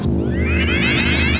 BSG FX - Basestar Laser
BSG_FX-Basestar_Laser.WAV